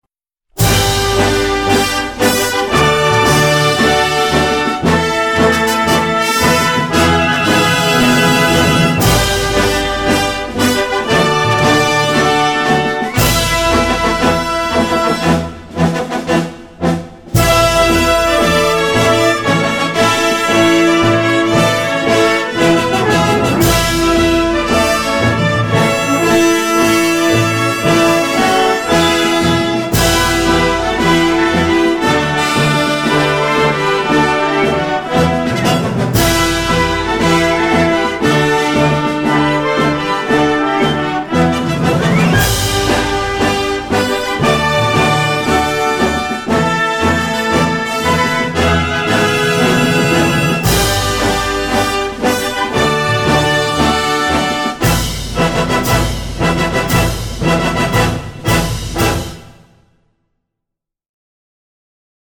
инструментальная версия